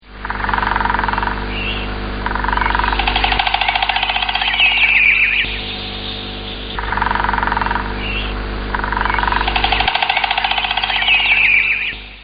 На ней вы услышите голоса некоторых птиц, в том числе - иволги, соловья красношейки, двух дятлов.
Трель дятла-желны
dyatel1.mp3